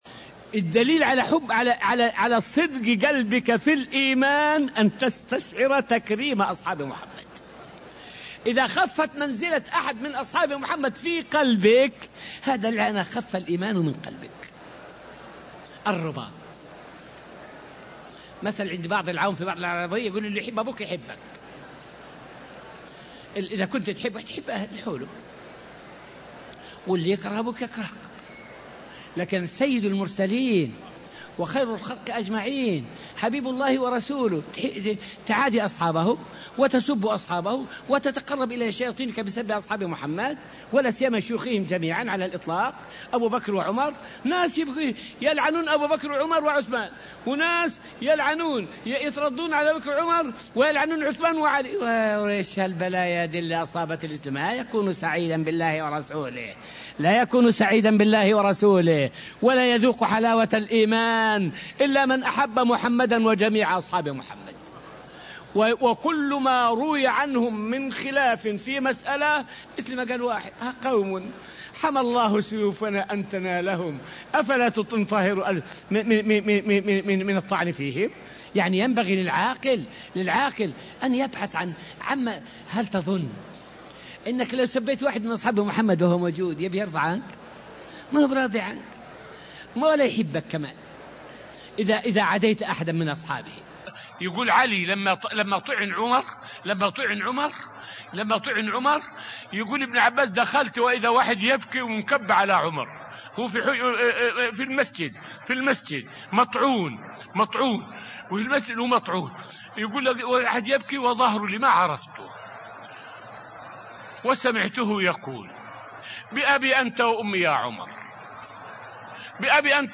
فائدة من الدرس الواحد والعشرون من دروس تفسير سورة البقرة والتي ألقيت في المسجد النبوي الشريف حول حب الصحابة دليل الإيمان.